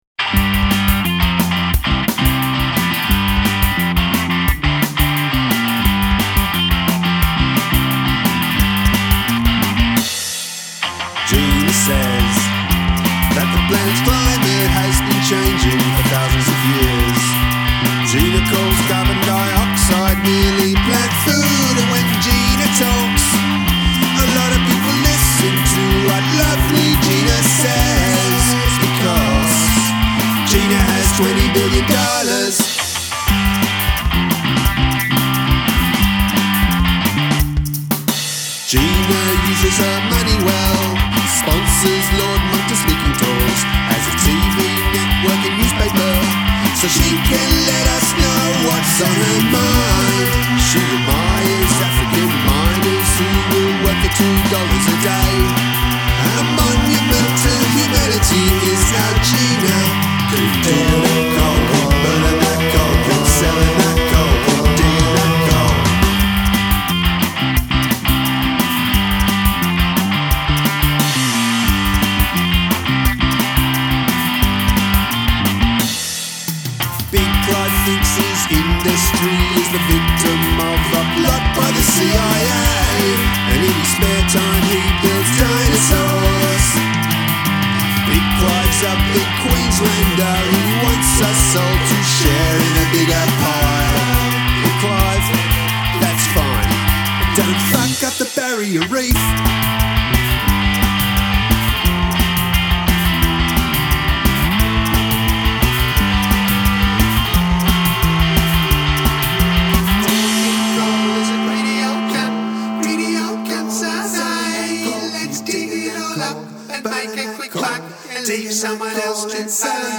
Use of Falsetto